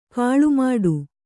♪ kāḷumāḍu